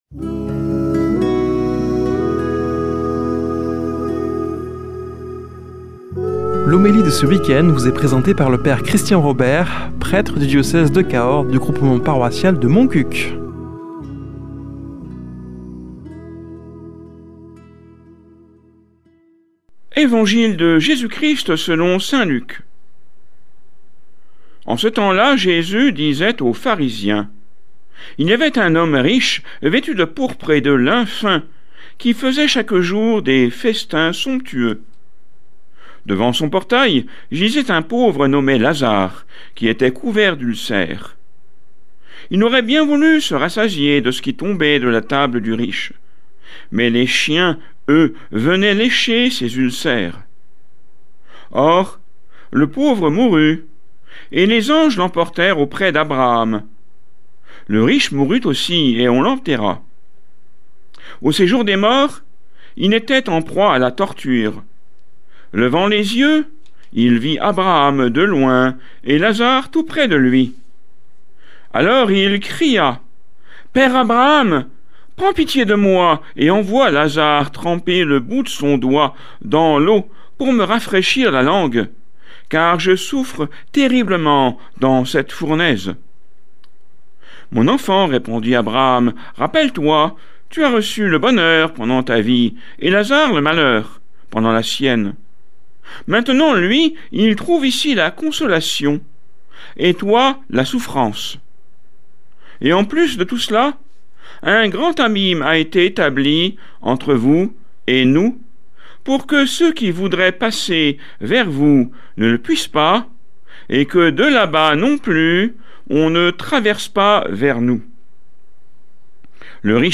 Homélie du 27 sept.